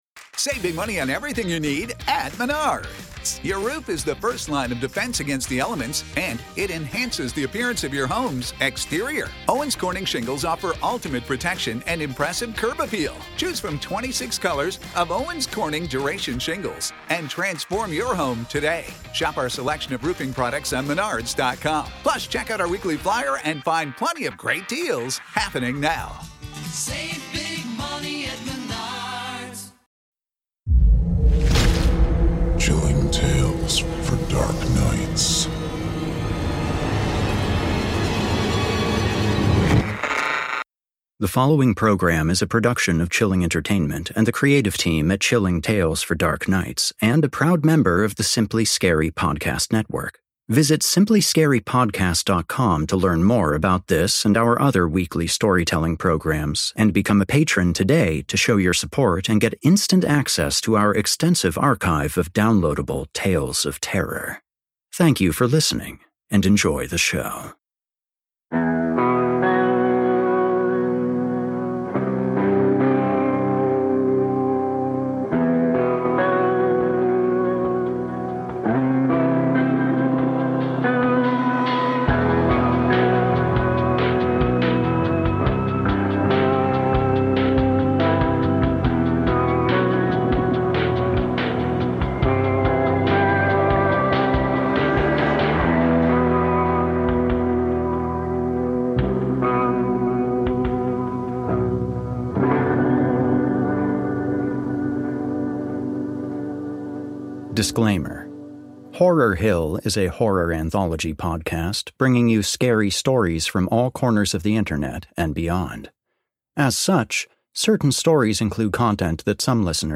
Horror Hill — A Horror Fiction Anthology and Scary Stories Series Podcast / S13E08 - "Neapolitan Ice Cream" - Horror Hill